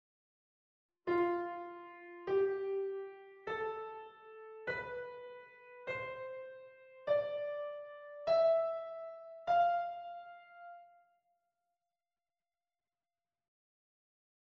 Lydian Mode
lydian-mode.mp3